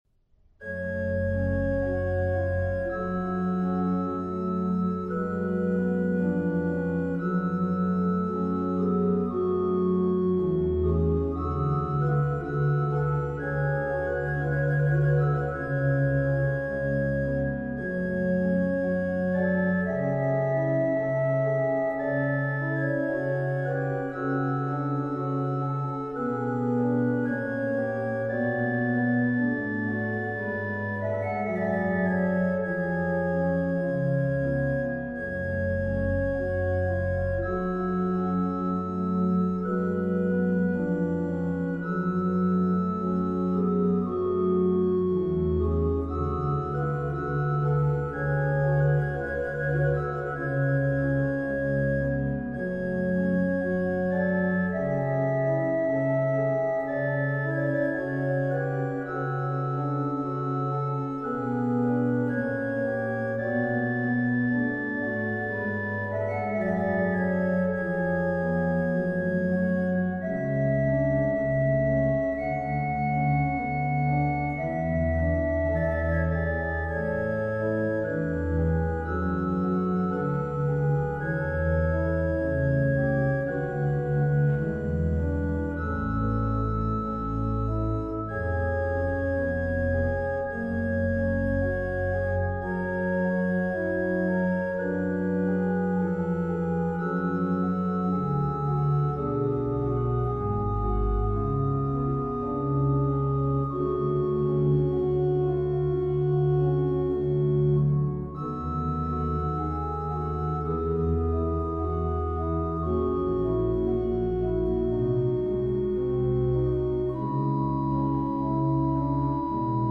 BWV 639, organ prelude to chorale